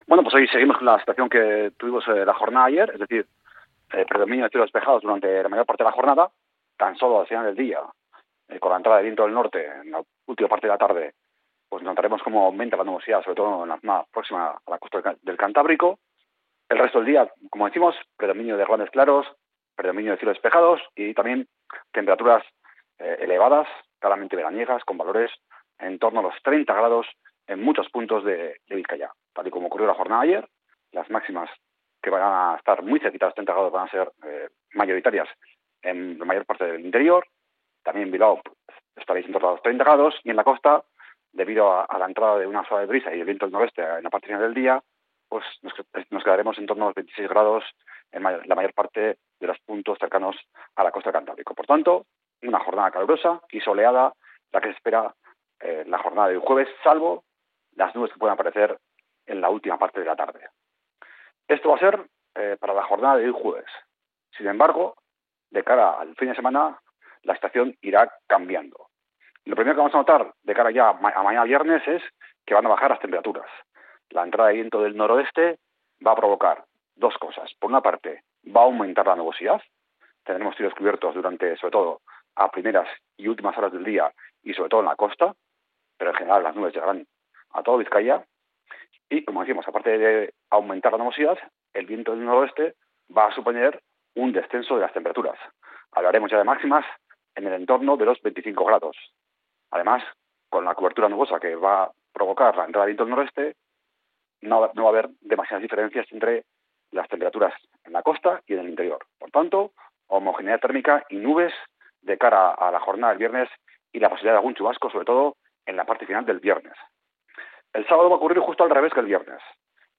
El pronóstico del tiempo para este 17 de julio